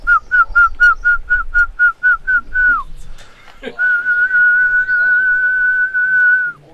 Mémoires et Patrimoines vivants - RaddO est une base de données d'archives iconographiques et sonores.
Sifflement pour faire uriner les boeufs